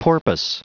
Prononciation du mot porpoise en anglais (fichier audio)
Prononciation du mot : porpoise